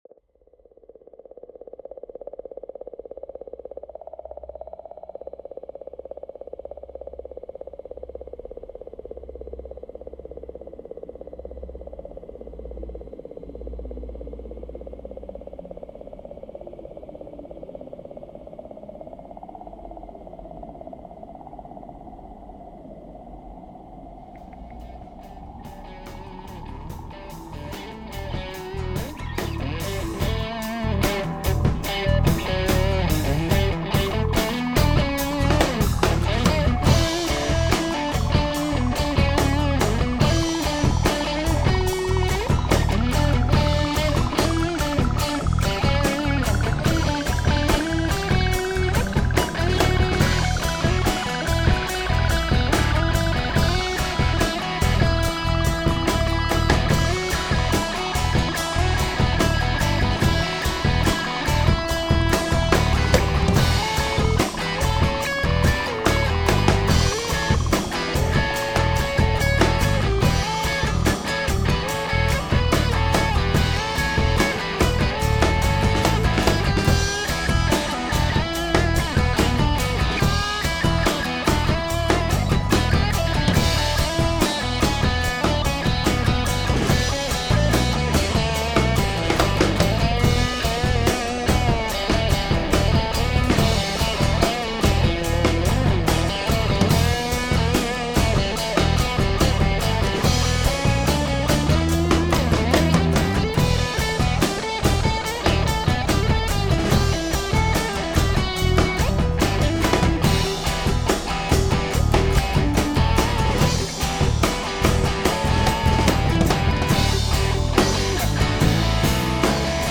All Original Indy Rock Sound